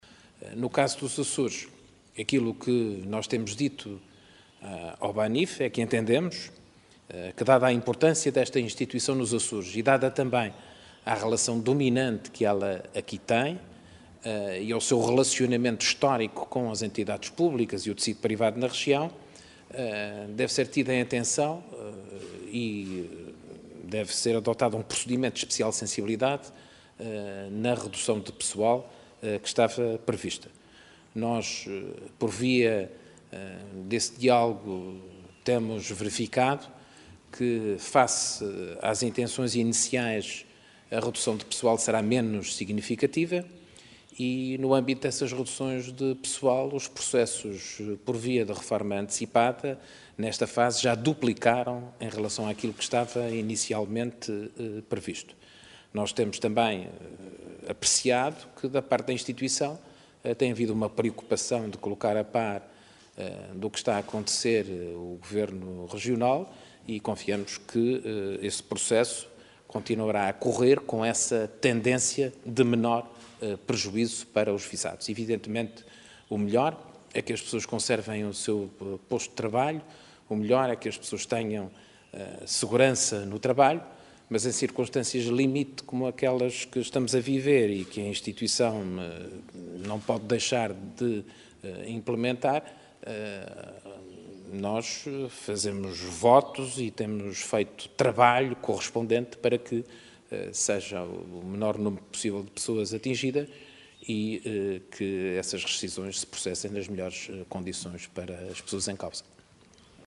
As declarações de Carlos César foram prestadas aos jornalistas após ter recebido em audiência no Palácio de Sant´Ana o Presidente do Conselho de Administração do BANIF, Luis Amado.